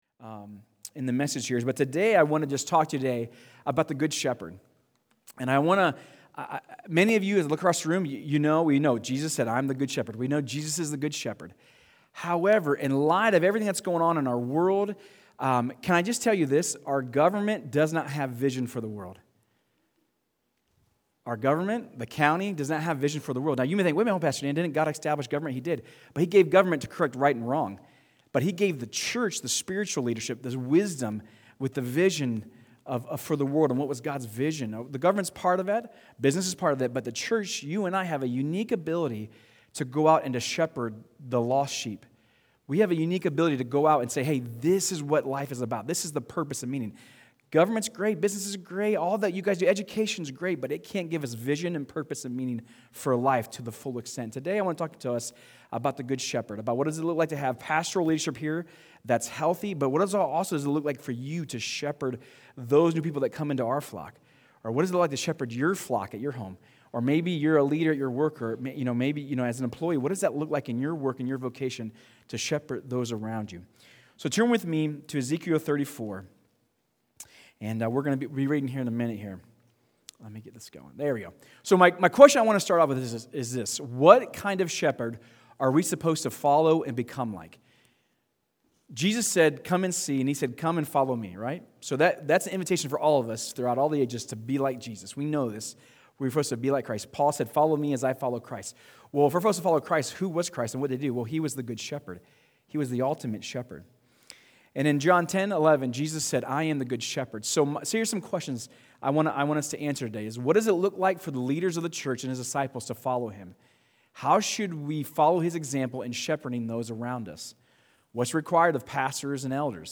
Sermons | Crosspointe Church